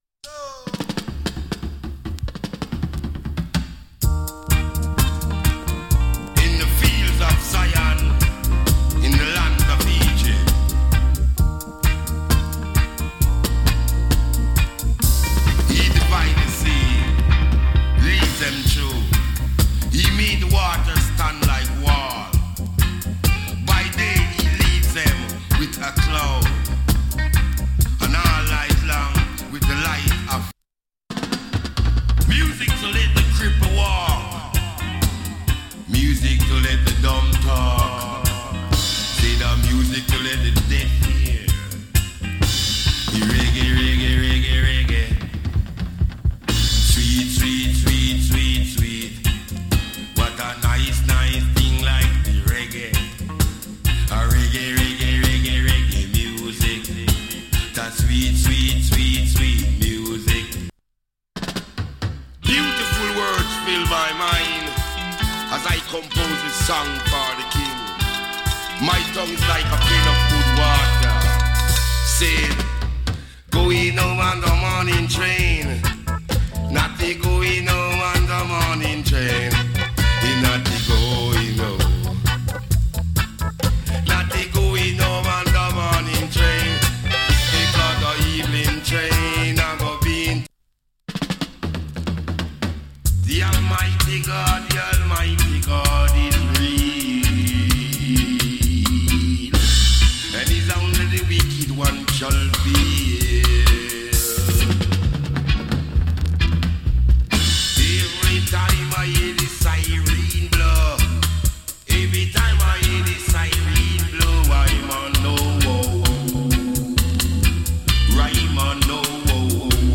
79年 KILLER ダミ声 DEE-JAY ALBUM.